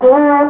Sons cors des Alpes
SONS ET LOOPS DE CORS DES ALPES
Banque sons : INSTRUMENTS A VENT